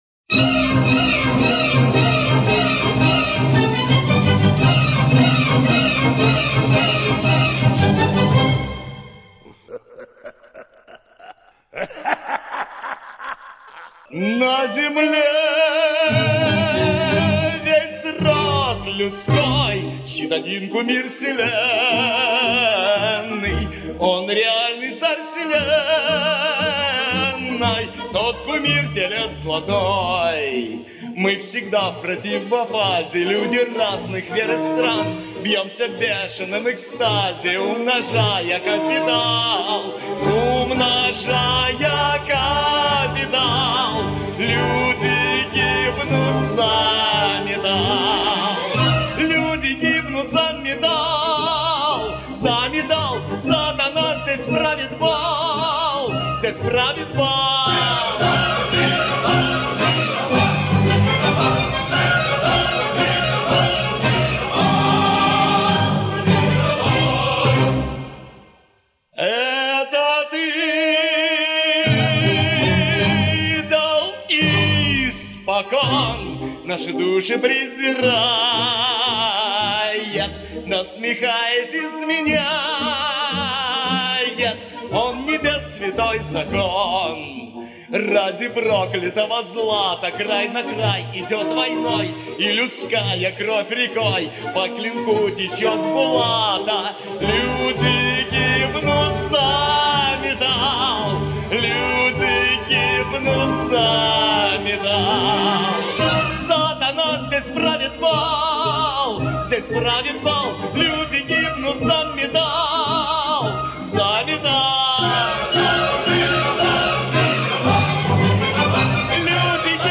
Вот и решил положить голос на классическую фонограмму.